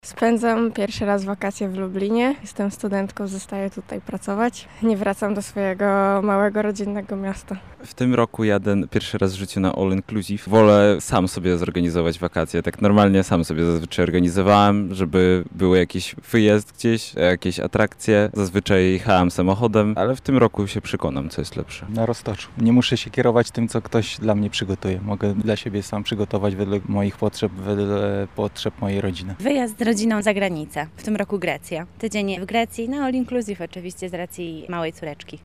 Zapytaliśmy Lublinian gdzie wyjadą na urlop:
SONDA
Sonda-wakacje.mp3